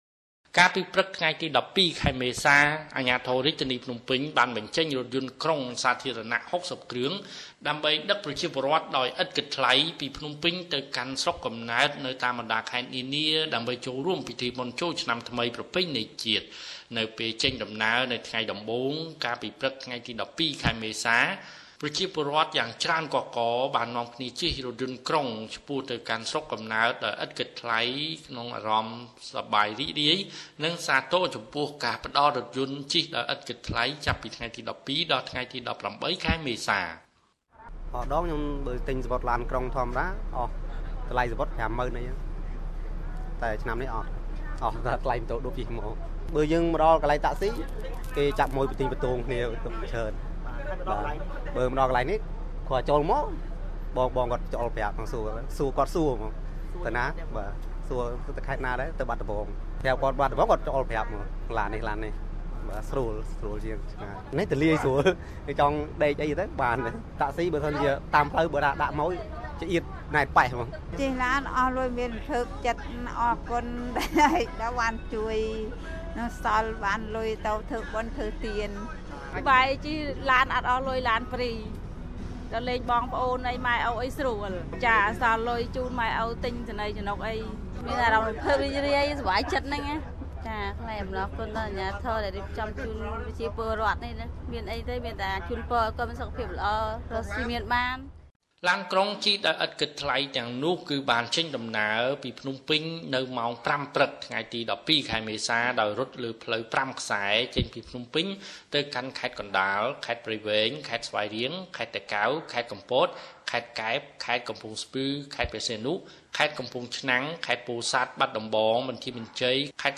( សំឡេងប្រជាពលរដ្ឋ )
( សំឡេងលោក ឃួង ស្រេង )